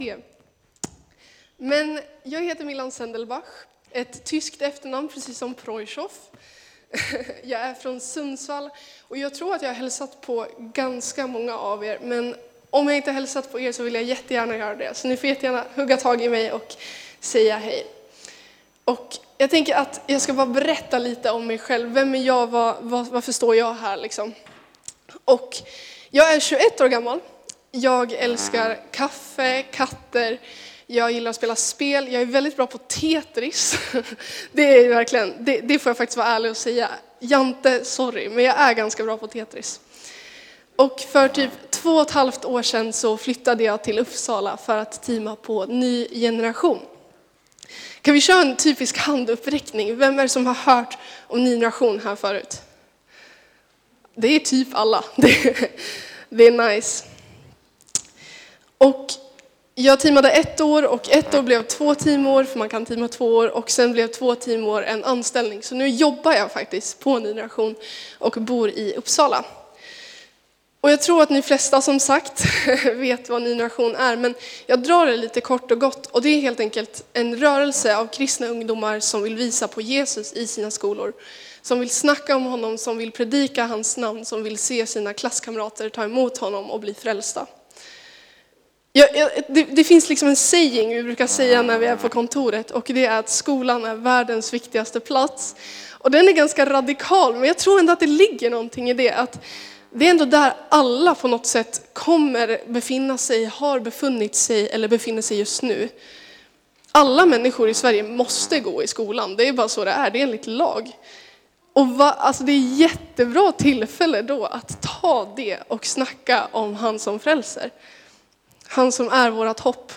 Predikan 12 januari
Furuhöjdskyrkan, Alunda